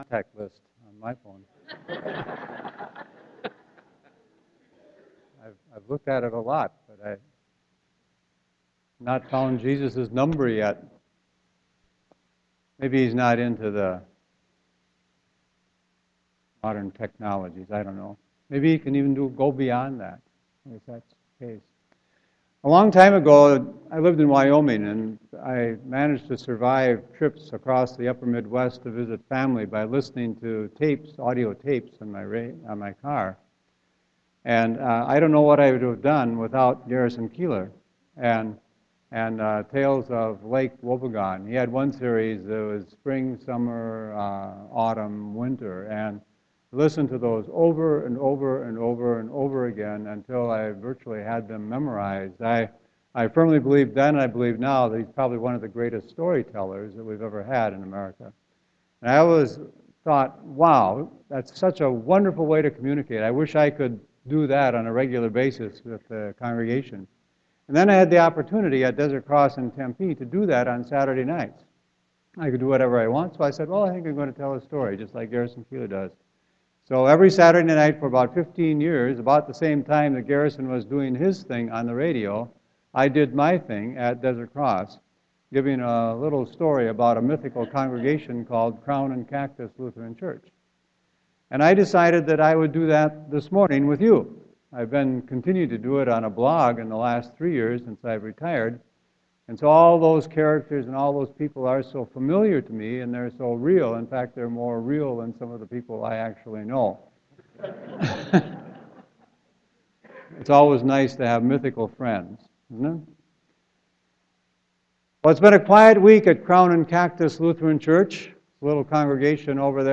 Sermon 11.15.2015